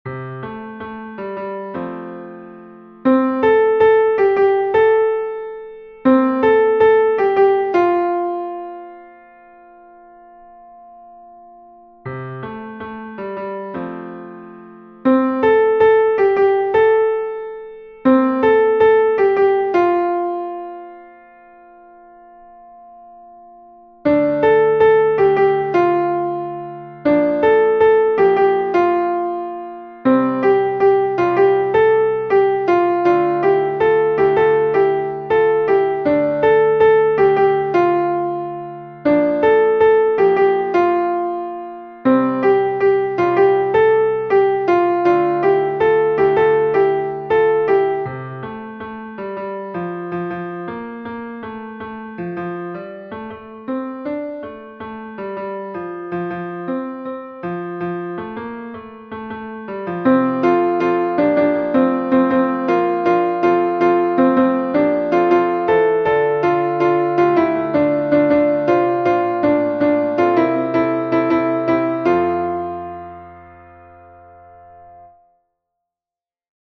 MP3 version piano
Tutti